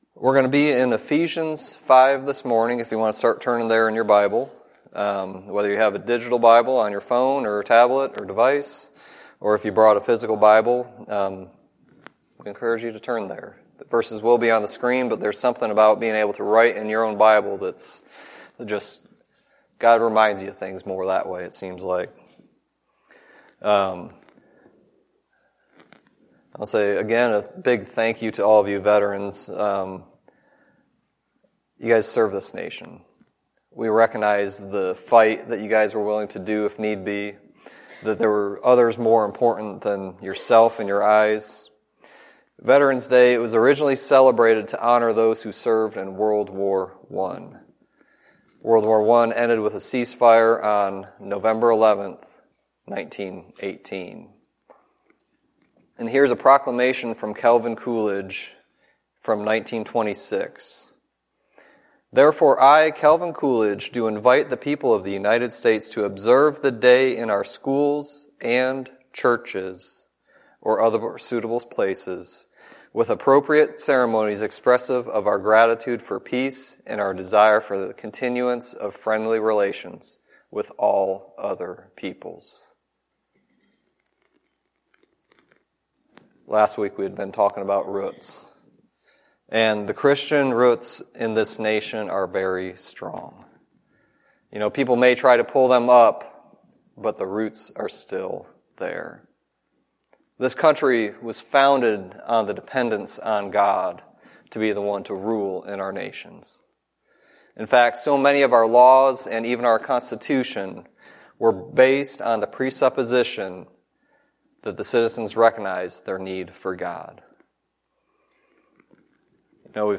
Sermon messages available online.
Micah 6:8 Service Type: Sunday Teaching Its important to recognize the relationship we have with God.